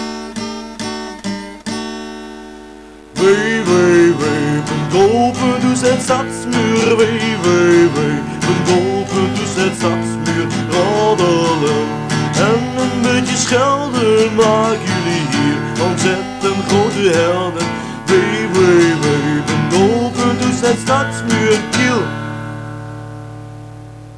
download de Achtergrondmuziek door